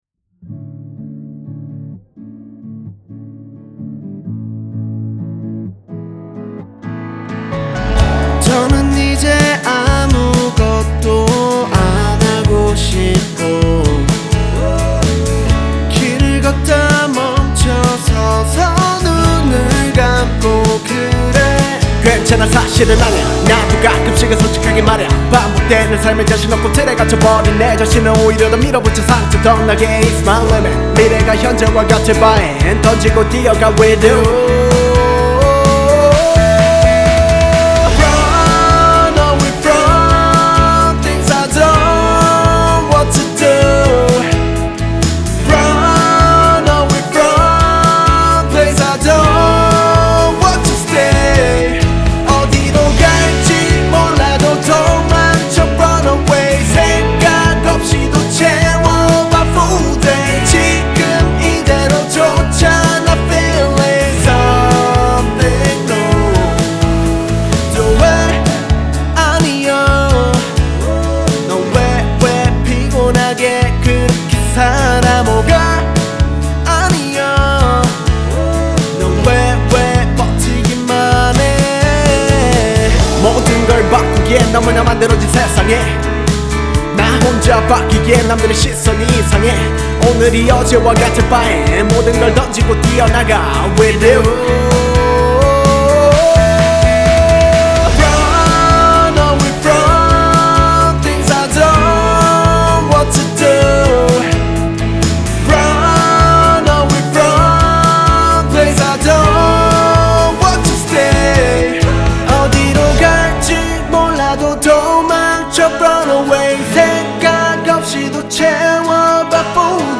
• Жанр: K-pop